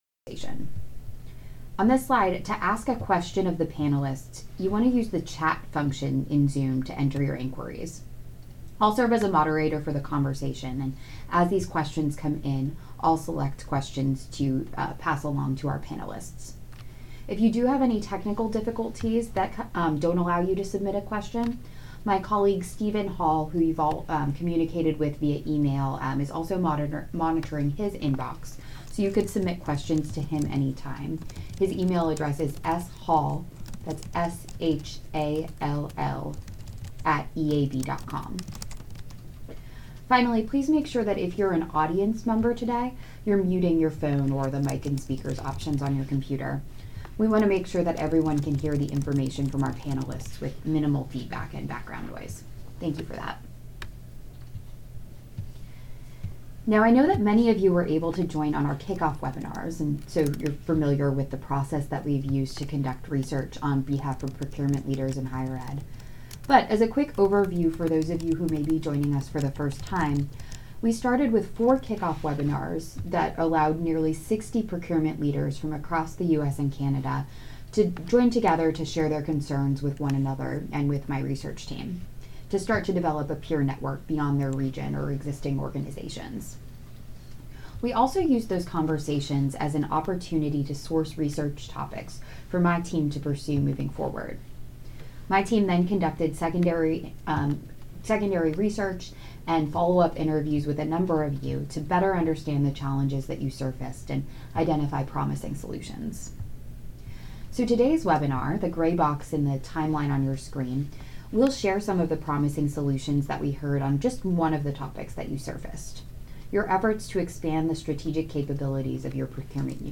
MP3-BAF-procurement-member-panel-webconference.mp3